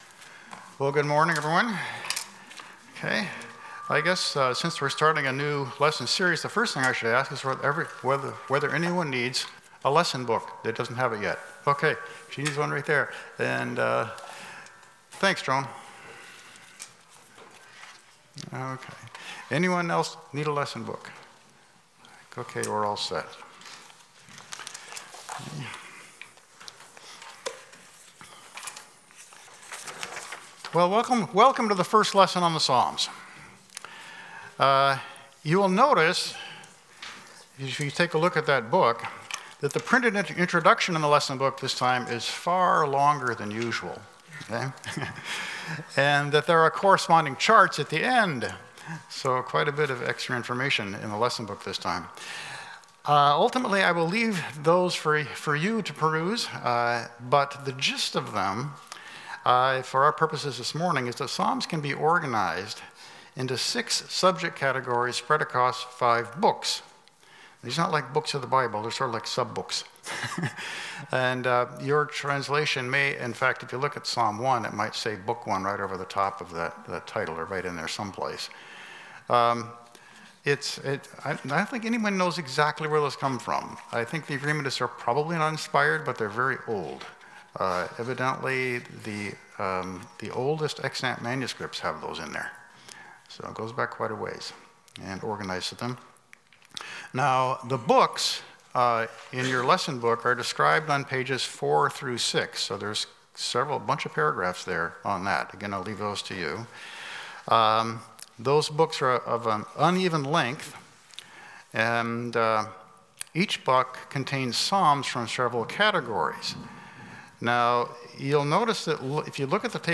Passage: Psalms 1-2 Service Type: Sunday School